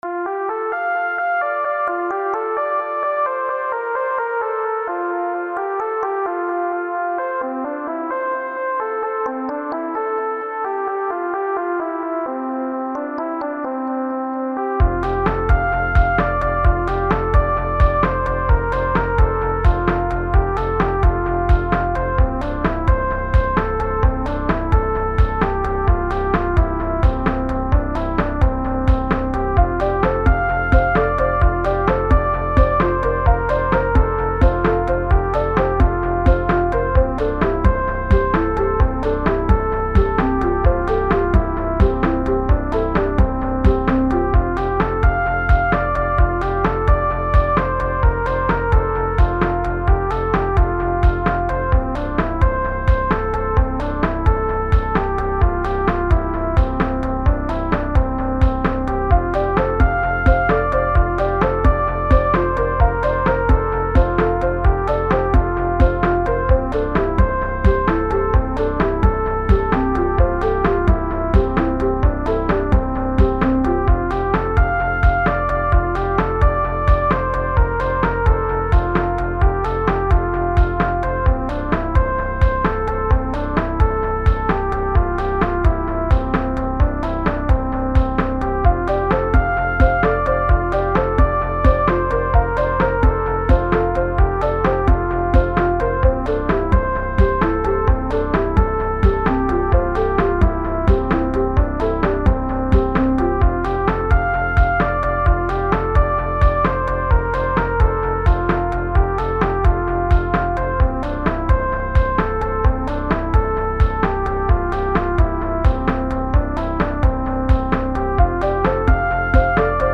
80s Music
Synthwave